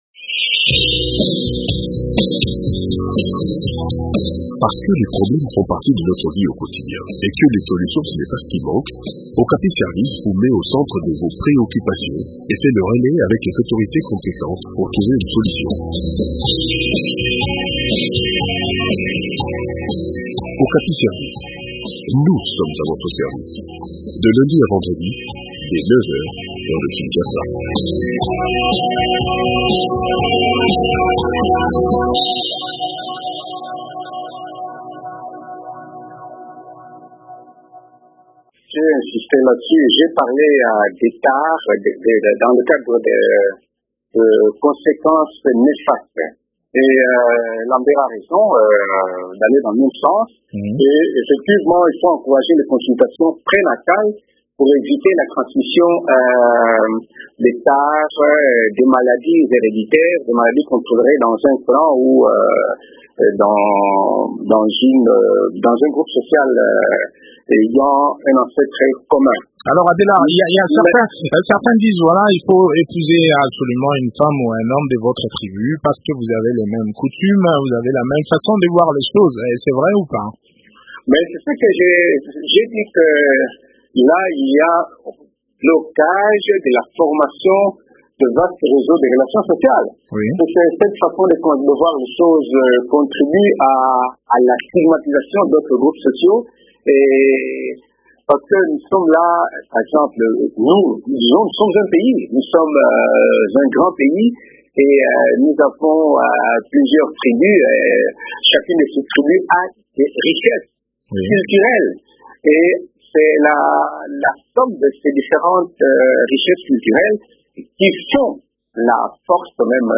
sociologue répond au micro